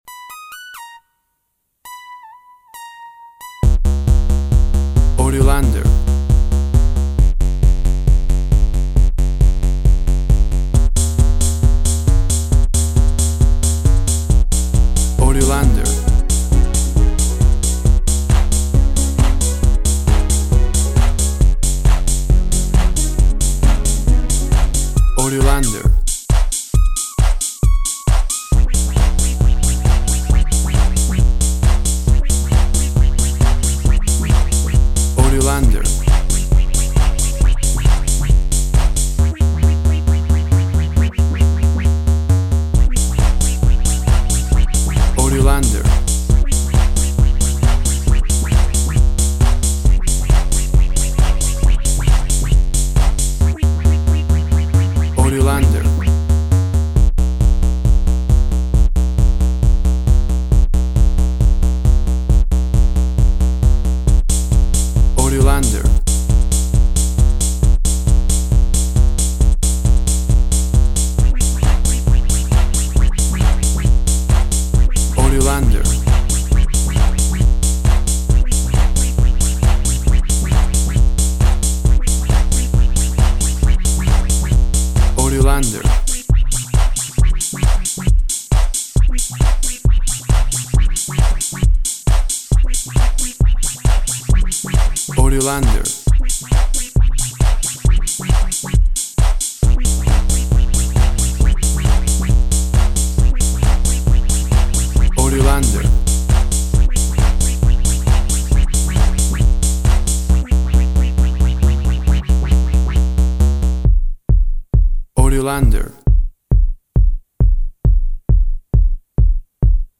WAV Sample Rate 16-Bit Stereo, 44.1 kHz
Tempo (BPM) 134